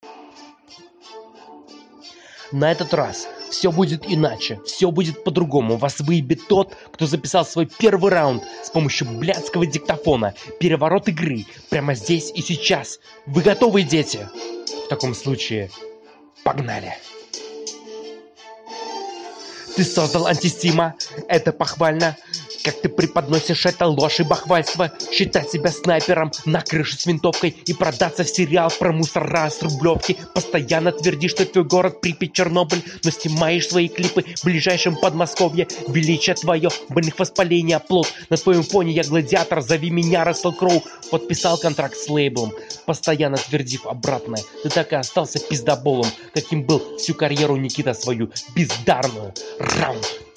Артистичный разговор под бит, записанный на диктофон - не впечатляет